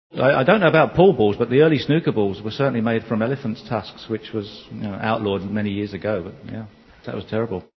wypowiedź Steve'a Davis'a [MP3-23KB].